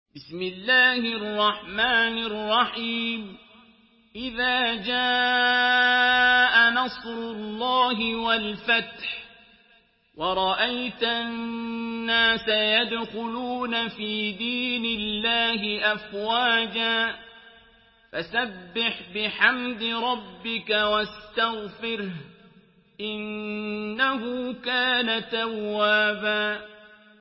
Surah Nasr MP3 by Abdul Basit Abd Alsamad in Hafs An Asim narration.
Murattal Hafs An Asim